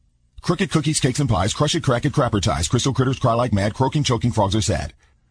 tongue_twister_03_03.mp3